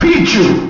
The announcer saying Pichu's name in Super Smash Bros. Melee.
Pichu_Announcer_SSBM.wav